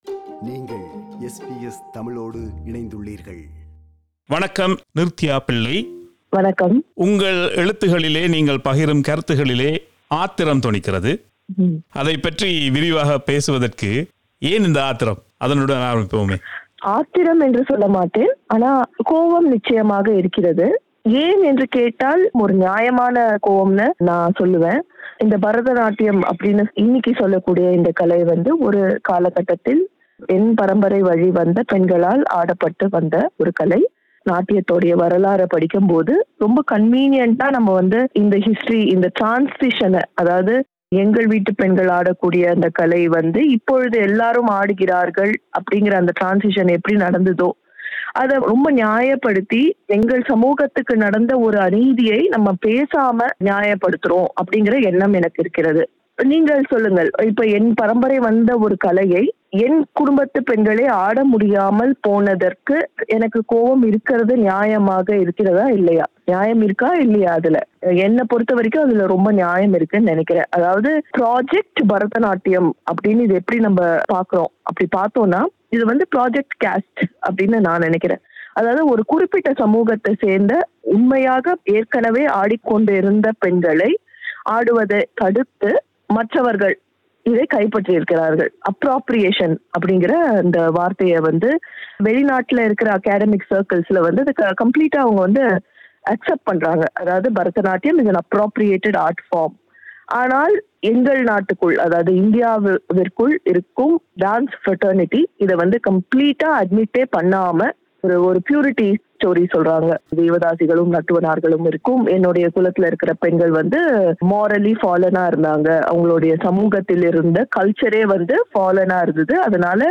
This is the first of the two-part interview.